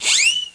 1 channel
ZING.mp3